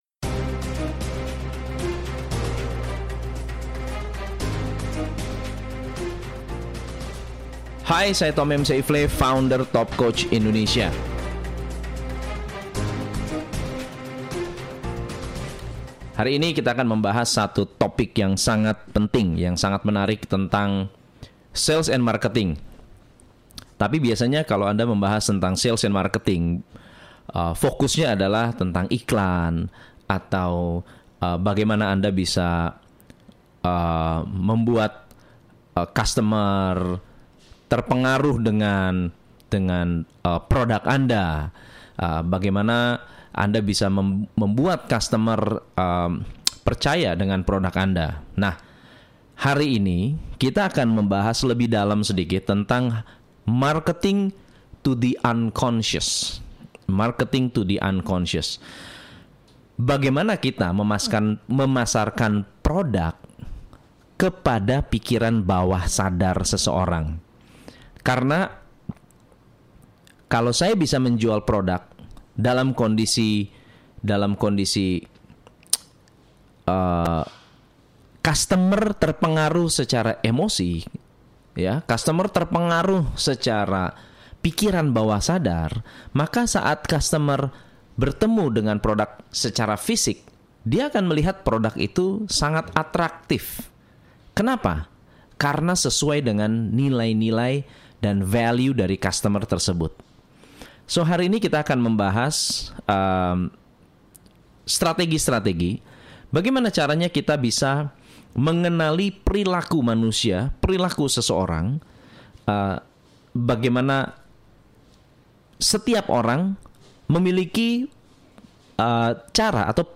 New Age Marketing Audioobook